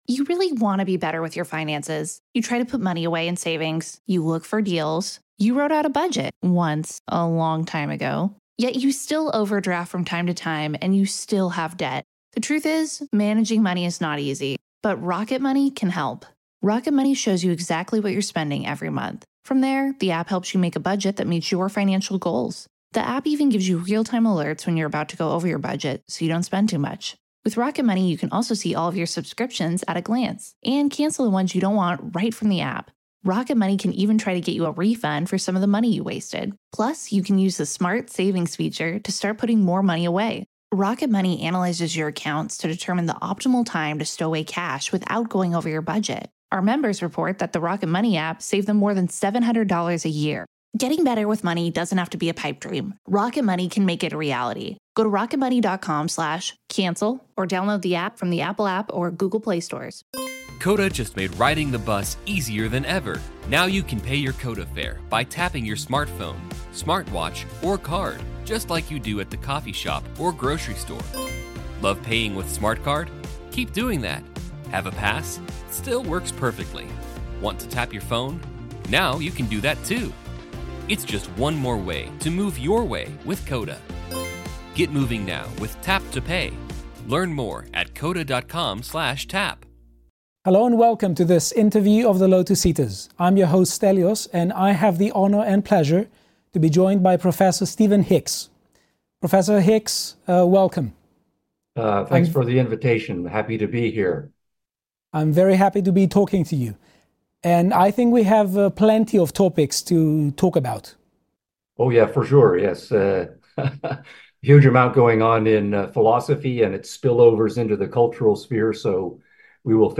Western Academia and Individualism | Interview with Prof Stephen Hicks